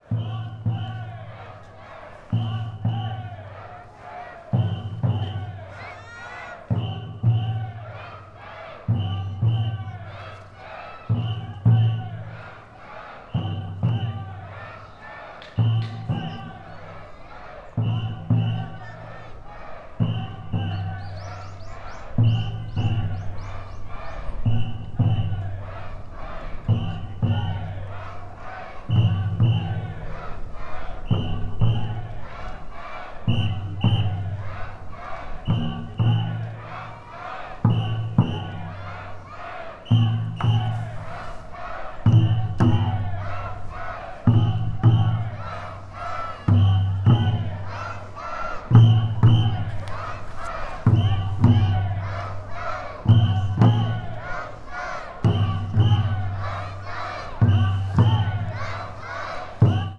Fukushima Soundscape: Mt. Shinobu